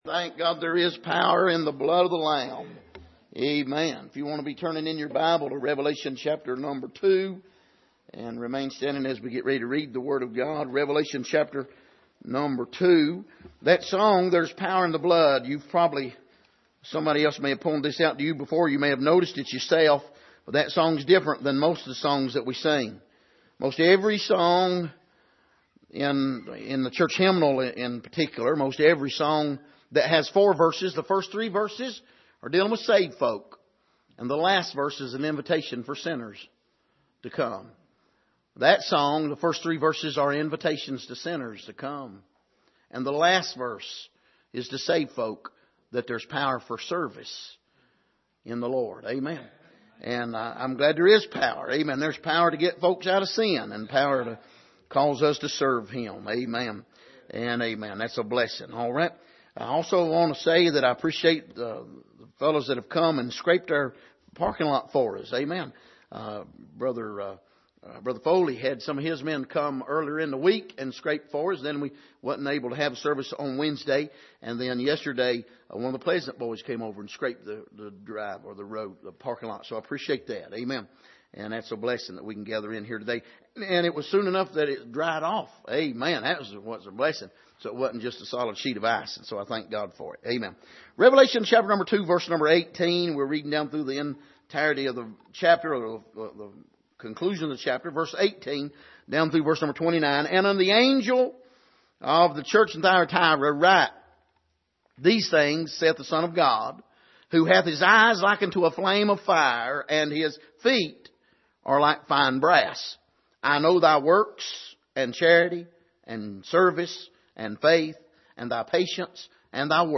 Passage: Revelation 2:18-29 Service: Sunday Morning